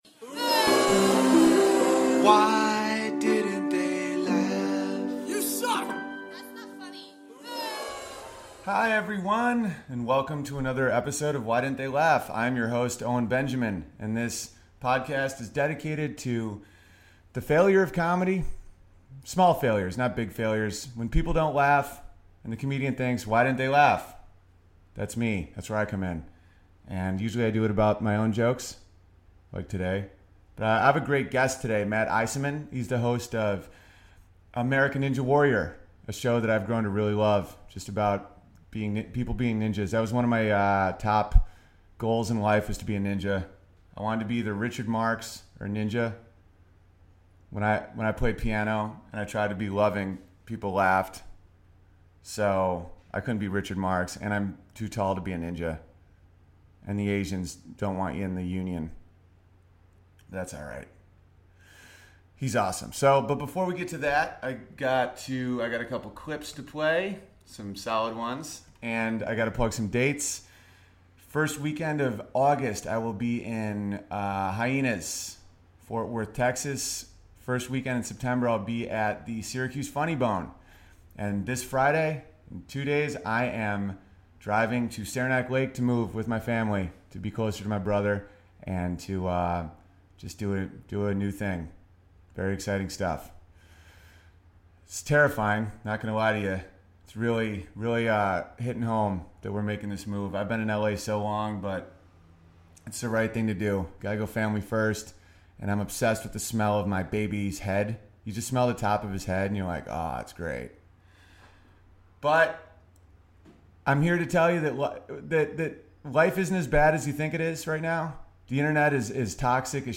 I read an awesome listener letter than spawns some solid solo conversation with myself.
I get to interview a long time friend, comedian, and great guy Matt Iseman who hosts "American Ninja Warrior" and talks about how and why he left the medical field.